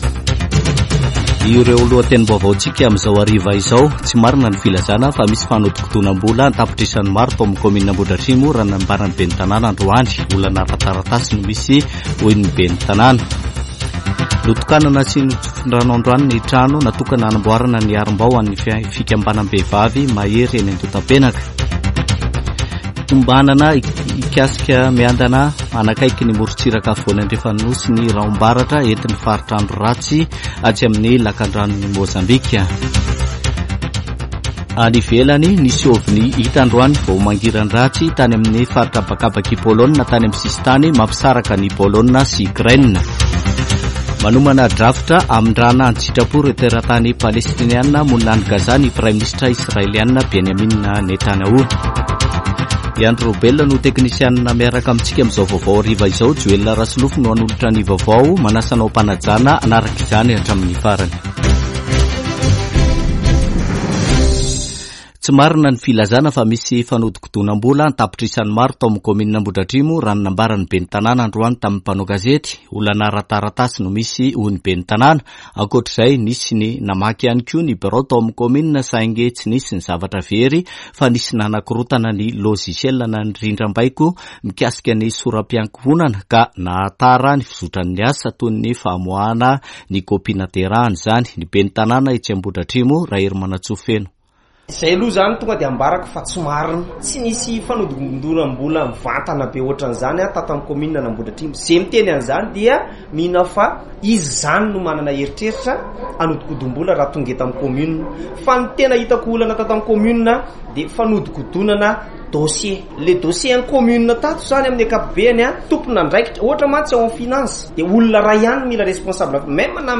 [Vaovao hariva] Zoma 29 desambra 2023